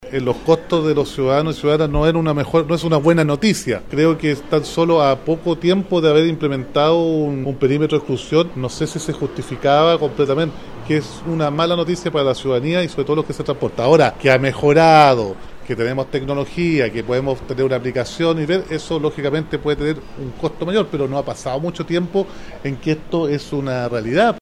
En tanto, el alcalde de Temuco, Roberto Neira, afirmó que el incremento de $20 en el pasaje adulto es una mala noticia. No obstante, destacó que el servicio ha mejorado.
cu-roberto-neira-x-alza.mp3